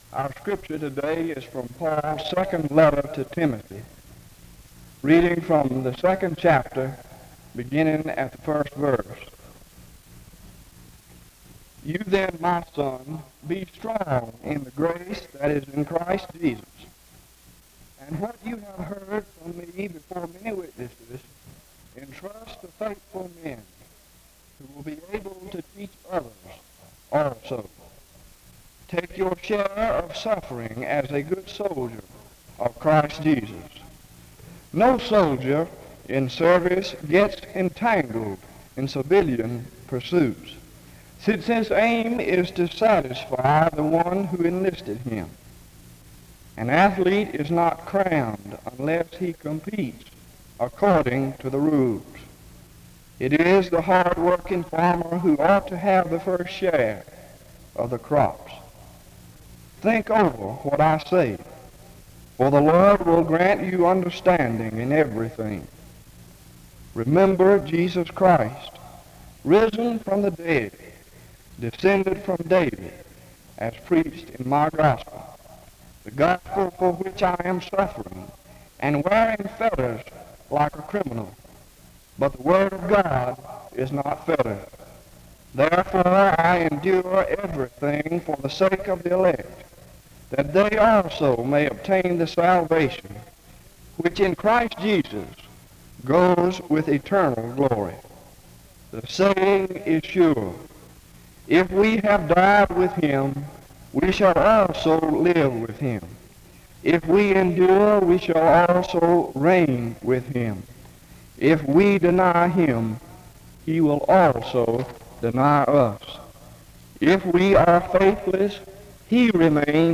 Download .mp3 Description The service begins with the reading of 2 Timothy 2:1-15 from 0:00-2:34. A prayer is offered from 2:35-4:09.
Students share their experiences and the opportunities provided for them at Southeastern from 6:20-21:41.